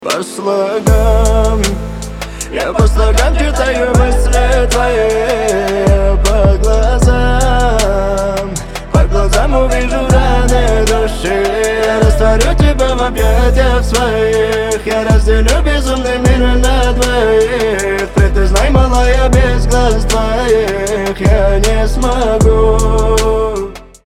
Медленный поп рингтон